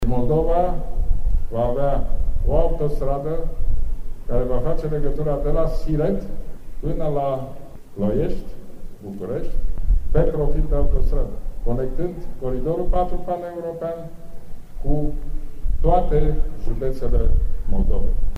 Prim-ministrul Ludovic Orban, preşedintele PNL, a prezentat, ieri la un eveniment de campanie electorală la Bacău, proiectele pe care Guvernul le are în infrastructura rutieră şi feroviară din judeţele Moldovei.